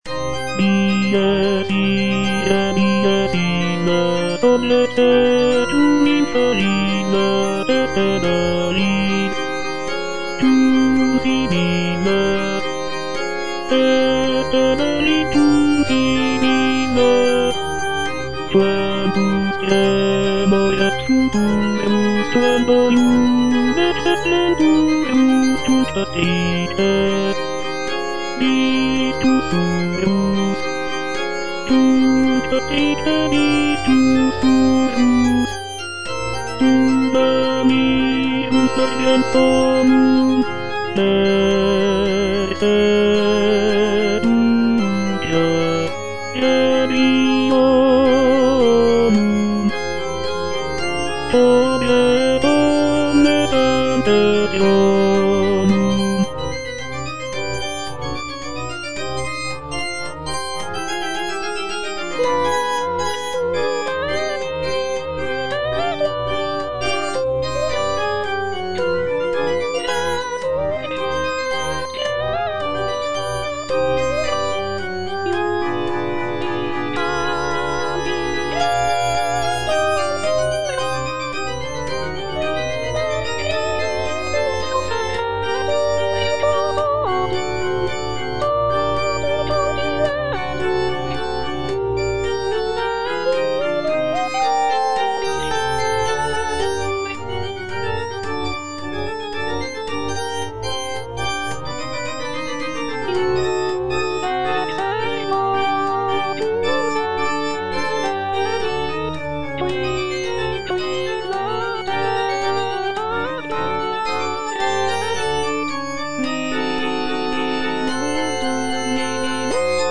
M. HAYDN - REQUIEM IN C (MISSA PRO DEFUNCTO ARCHIEPISCOPO SIGISMUNDO) MH155 Sequentia - Tenor (Voice with metronome) Ads stop: auto-stop
The work is characterized by its somber and mournful tone, reflecting the solemnity of a funeral mass.